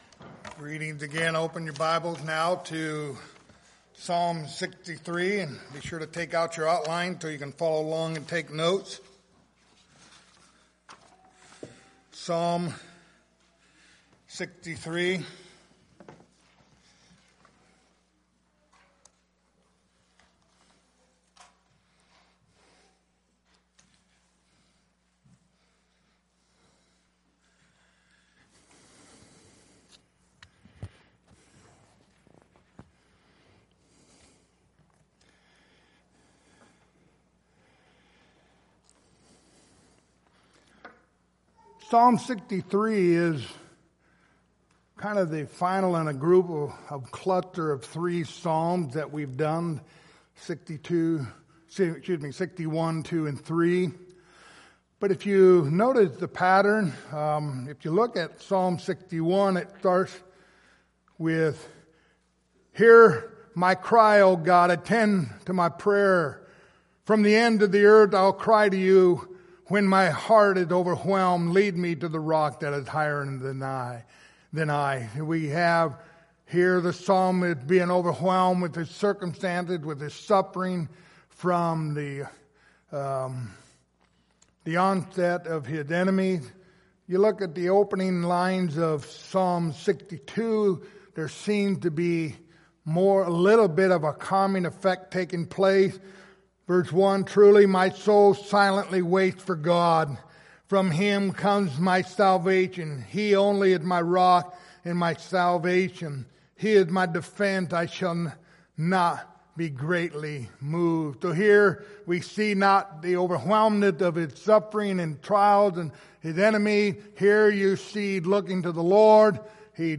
Passage: Psalms 63:1-11 Service Type: Sunday Morning